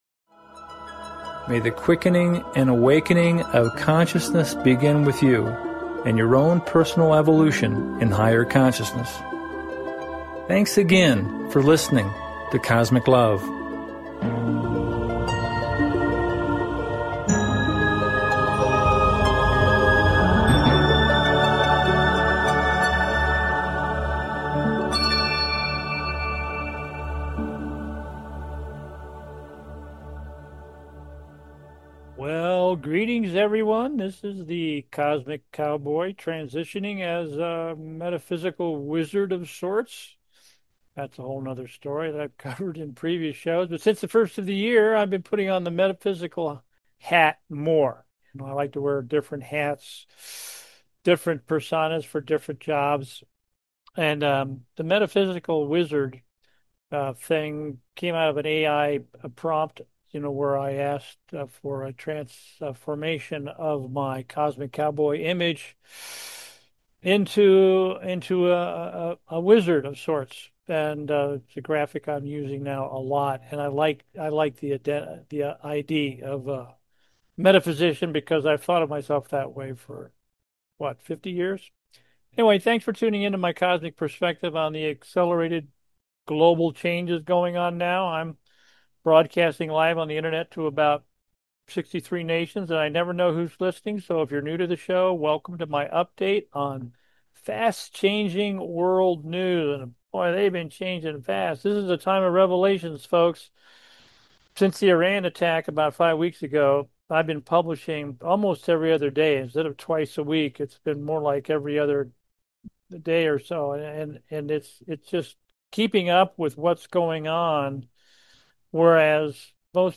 Narration from the graphic newsletter at:Resurrection and Ascent of Earth